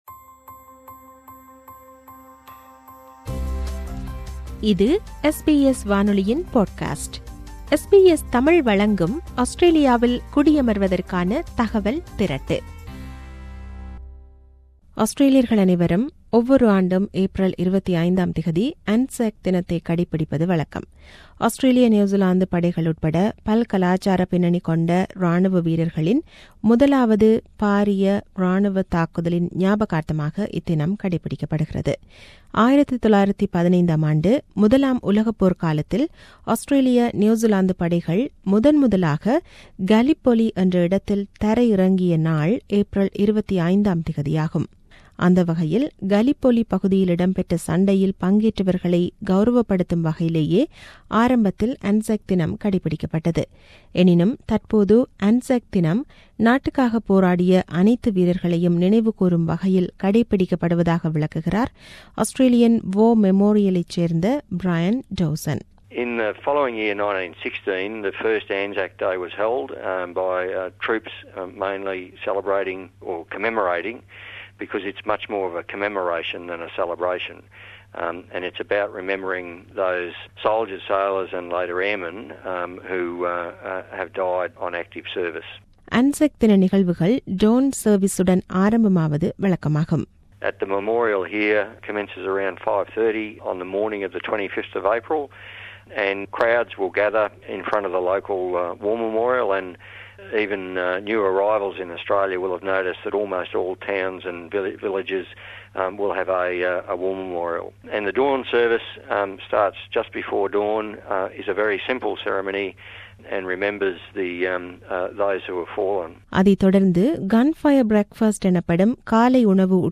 செய்தி விவரணத்தைத் தமிழில் தருகிறார்